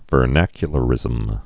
(vər-năkyə-lə-rĭzəm)